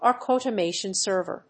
アークオートメーションサーバー